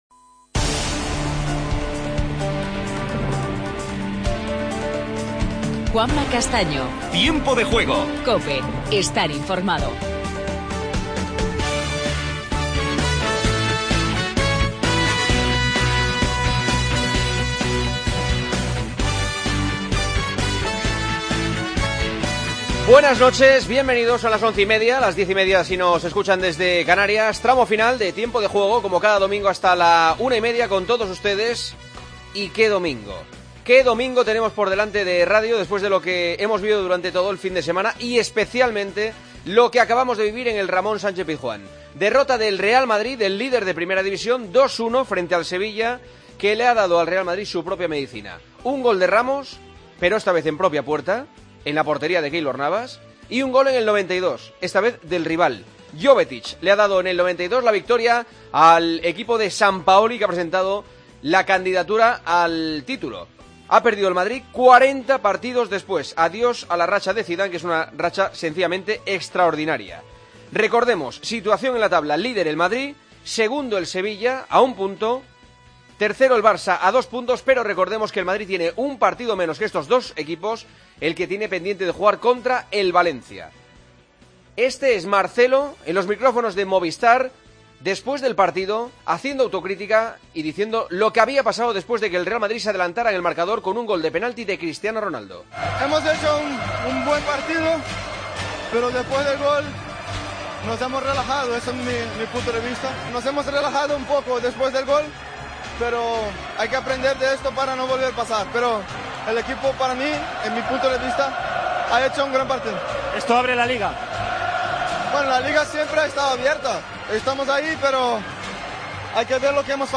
Redacción digital Madrid - Publicado el 15 ene 2017, 23:32 - Actualizado 17 mar 2023, 14:12 1 min lectura Descargar Facebook Twitter Whatsapp Telegram Enviar por email Copiar enlace Titulares del día. El Sevilla provoca la primera derrota del Real Madrid tras 40 partidos sin perder. Escuchamos a Sergio Ramos, Carvajal e Iborra.